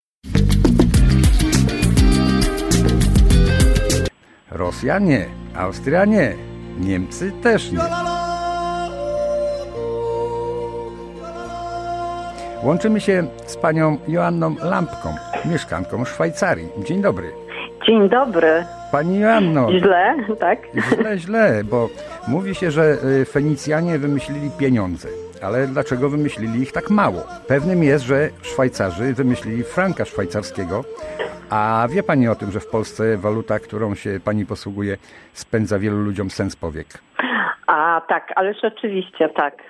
Zapowiedź audycji w załączonym materiale dźwiękowym.